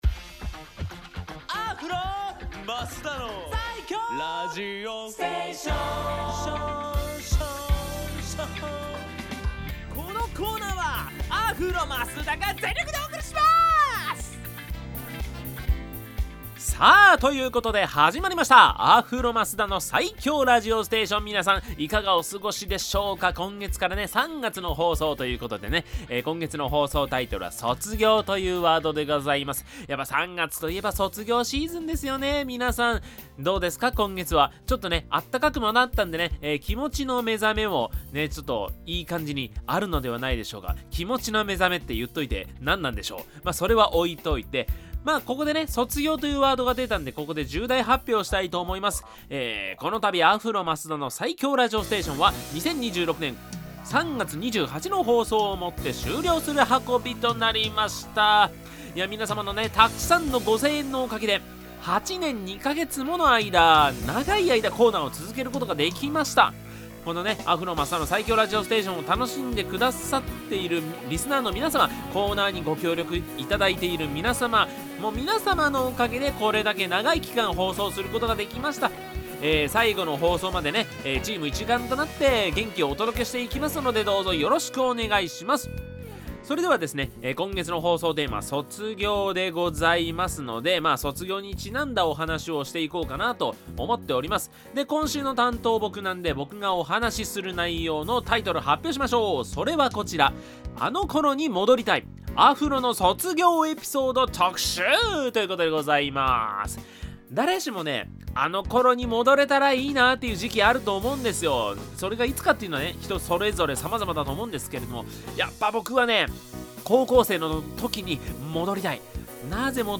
こちらのブログでは、FM83.1Mhzレディオ湘南にて放送されたラジオ番組「湘南MUSICTOWN Z」内の湘南ミュージックシーンを活性化させる新コーナー！
こちらが放送音源です♪